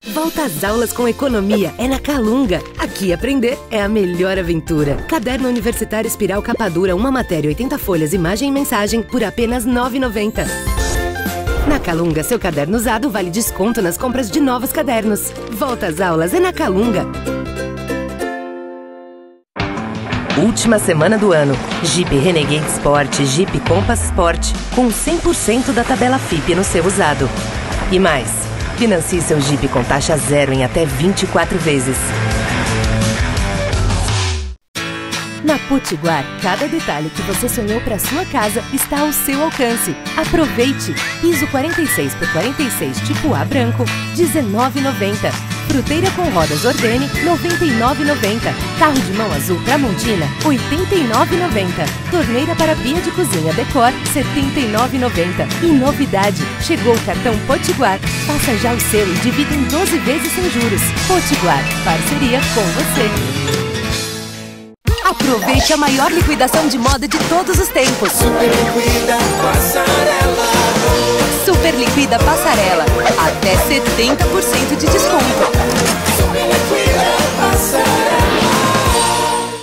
Feminino
Voz Jovem 01:19